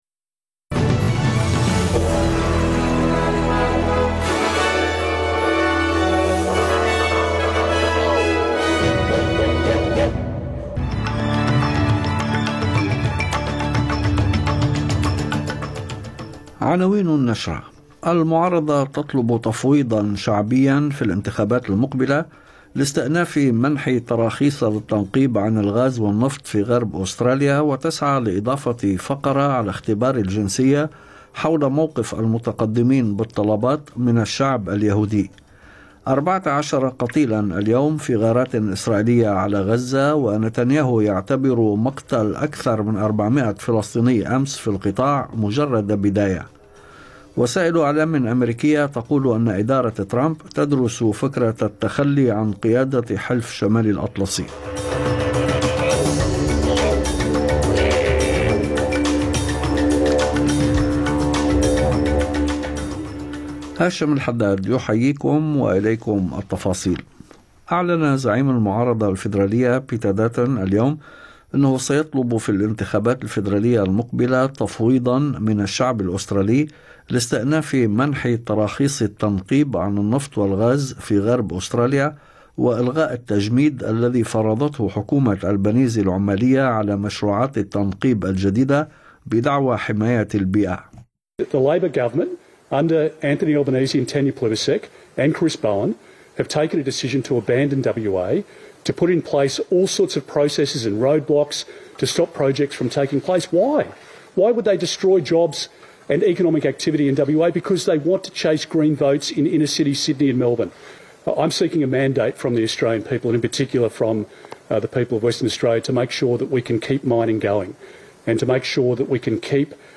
نشرة أخبار المساء 19/3/2025